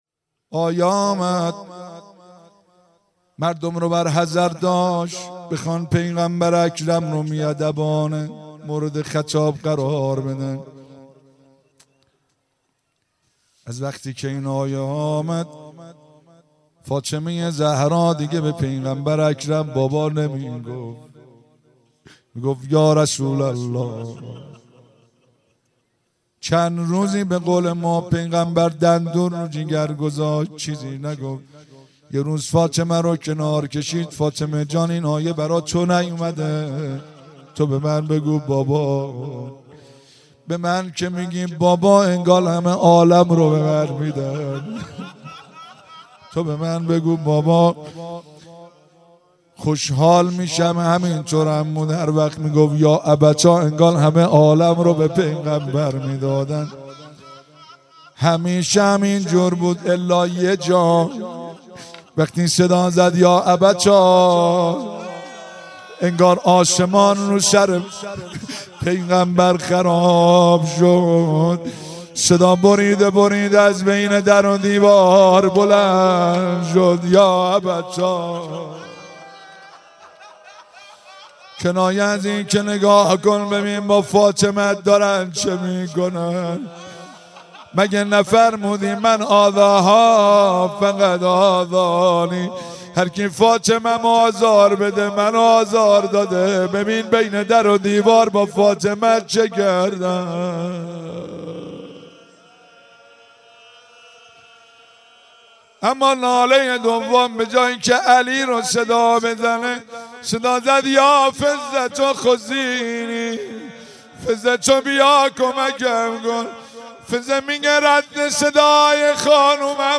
در حرم حضرت معصومه سلام الله علیها